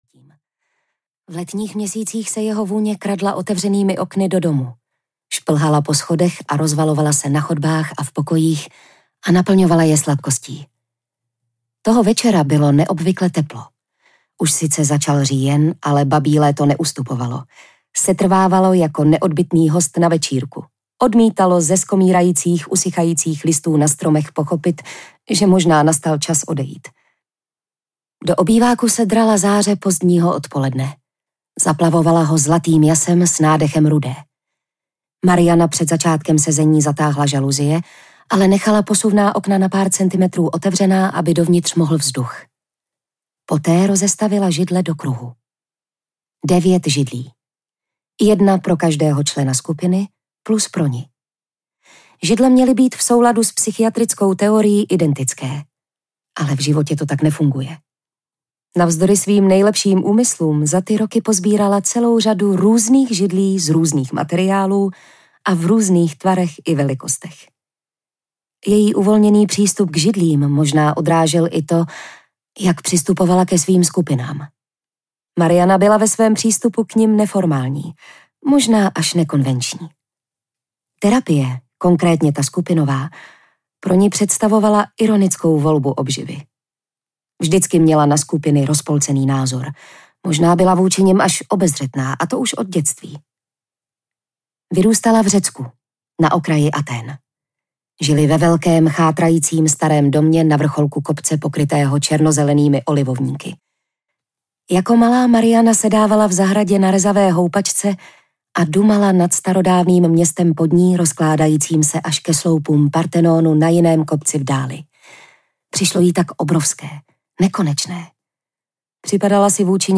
Panny audiokniha
Ukázka z knihy